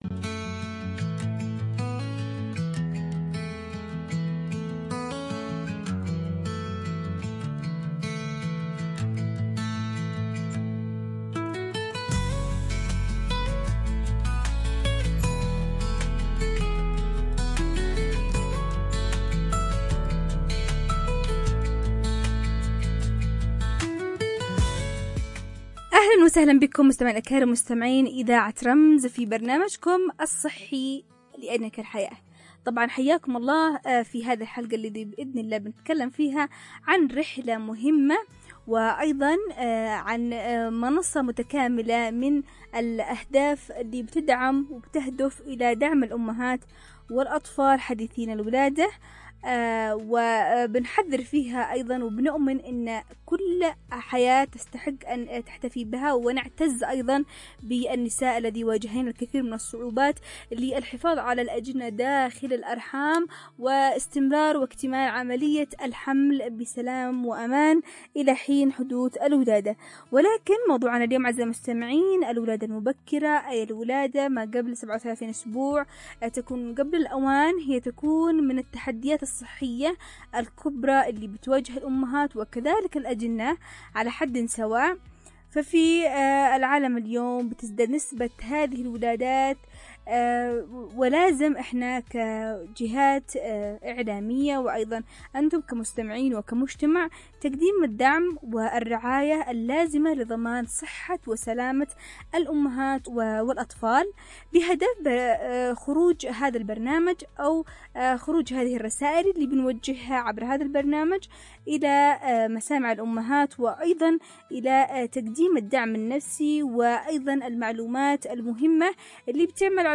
لتُناقش في حوار توعوي دقيق أبرز علامات الولادة قبل الأوان
عبر أثير إذاعة رمز